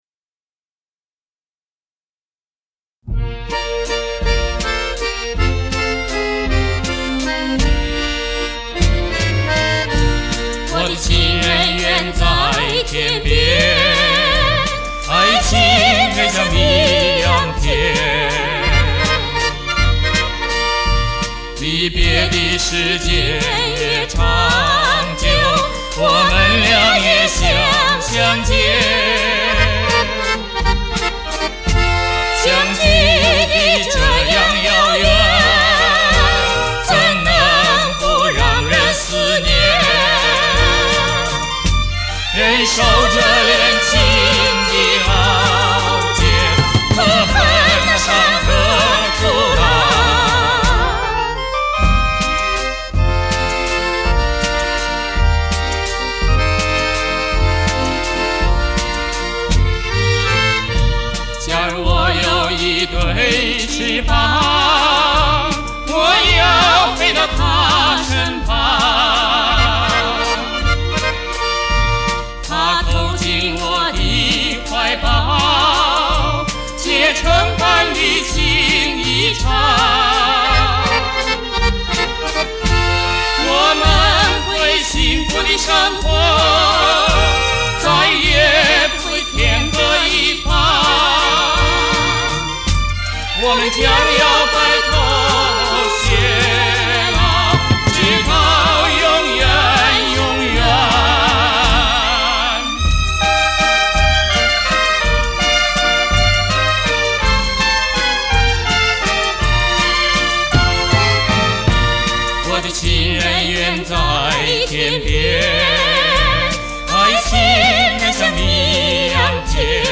在弄这张唱片的时候，求朋友重新录制并处理了一版，感觉比以前的音质提升了很多